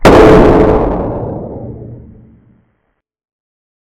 explosion0.ogg